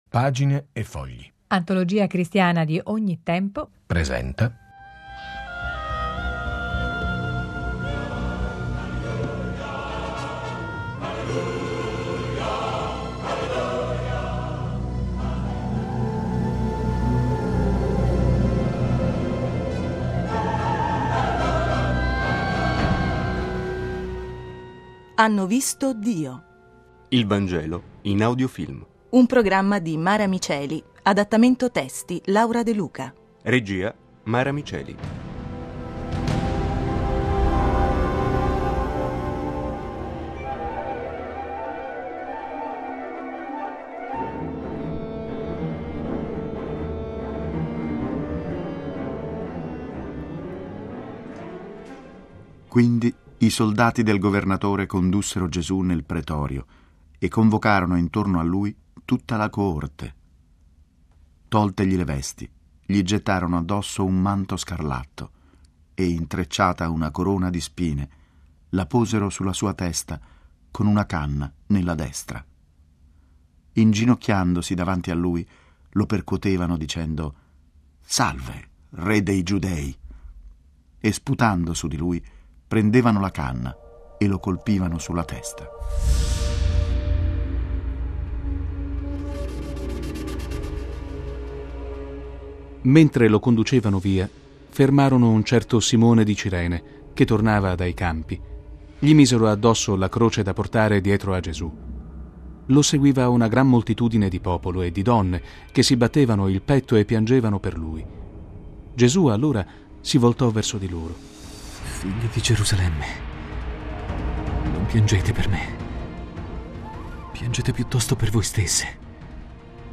HANNO VISTO DIO: Il Vangelo in Audiofilm. 15 e ultima Puntata